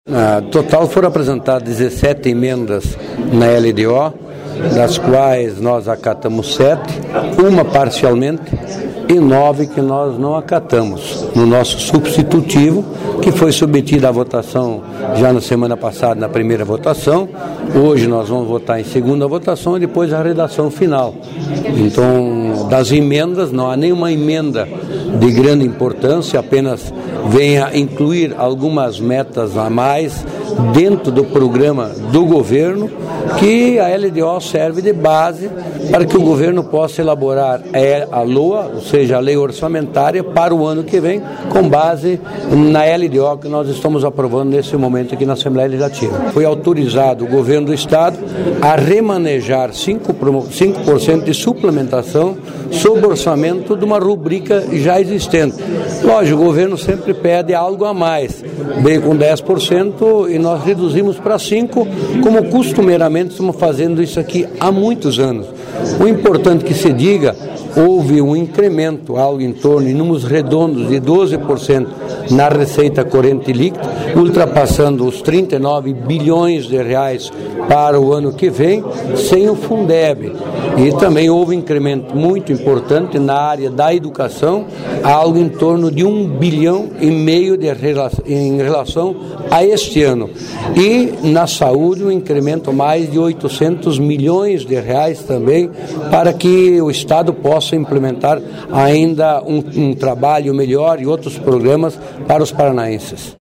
O deputado Elio Rusch, do DEM, relator da Comissão de Orçamento da Assembleia, falou sobre as emendas e do valor total apresentado.//